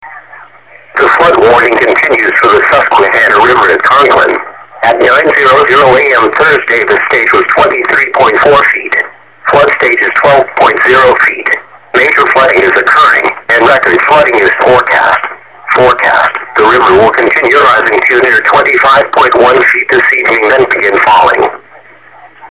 Flood Warning - Susquehanna River at Conklin